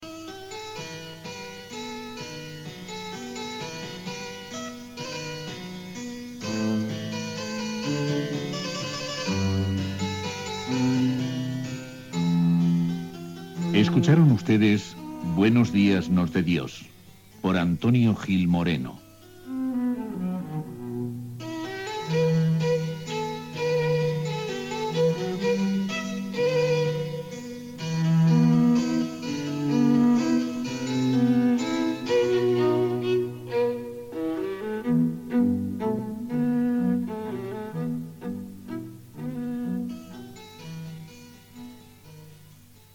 Careta de sortida del programa